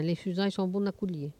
Maraîchin
Patois
Locution